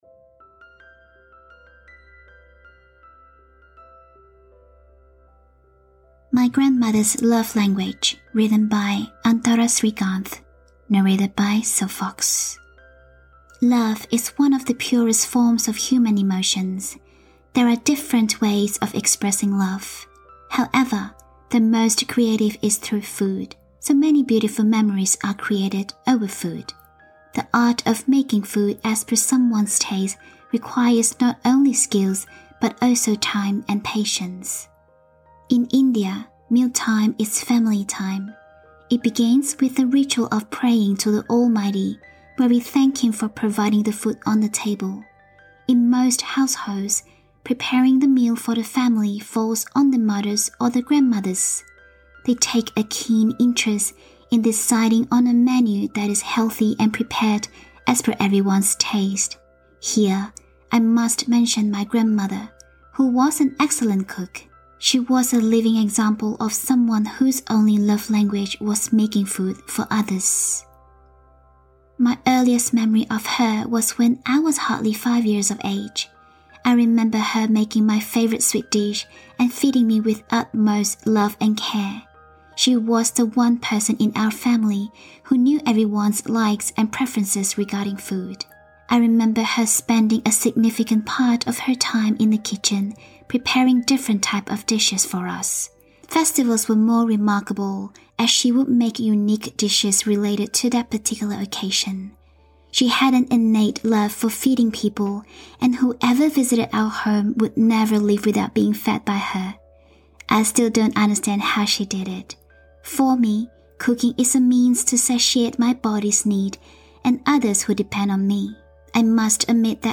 Audio Drama
Soothing Audiobooks